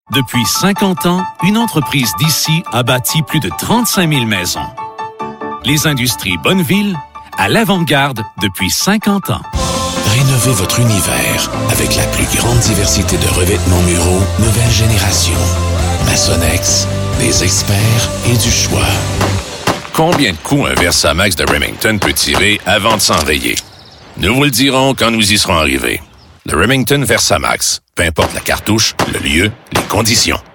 French-Canadian, Male, Studio, 30s-50s.